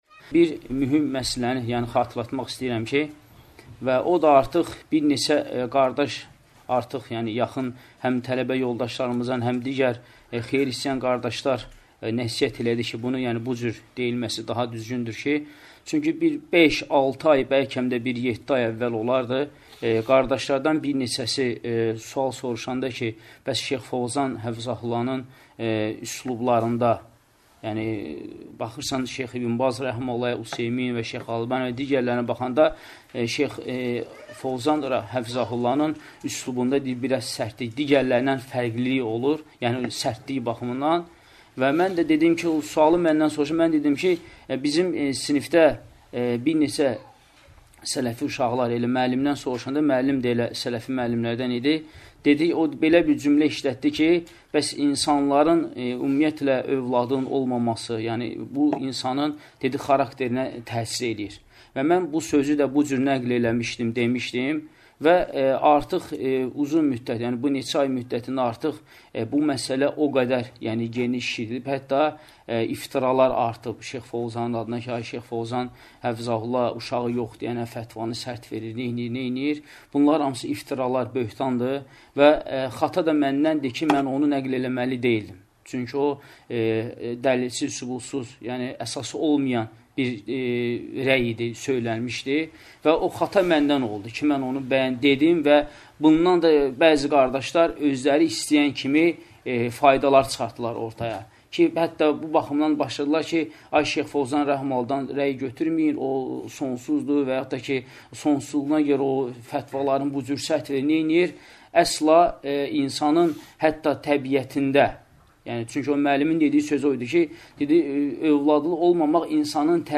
Dərslərdən alıntılar – 36 parça